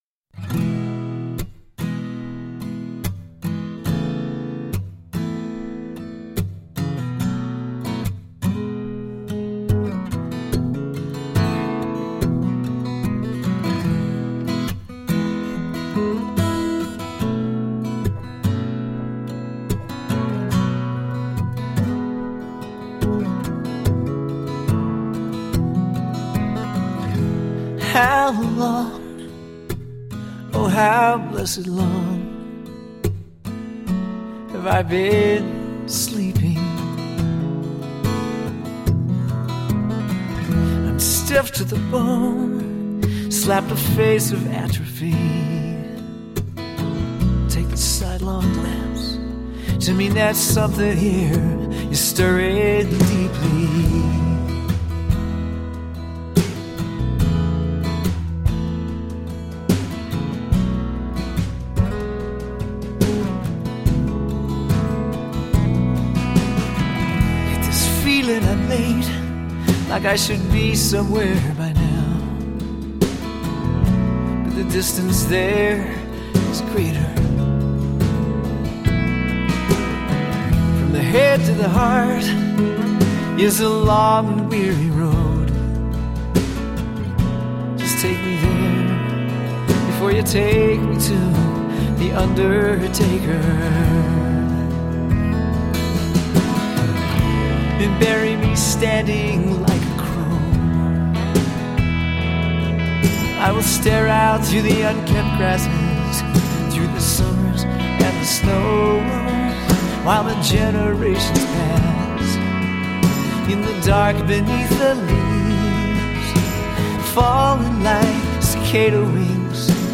Folk/country rock songs..
Tagged as: Alt Rock, Folk-Rock, Folk